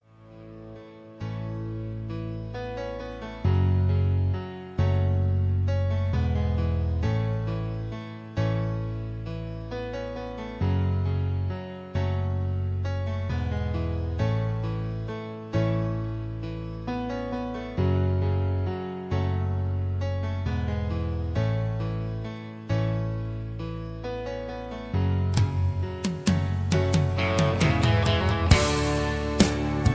karaoke collection , backing tracks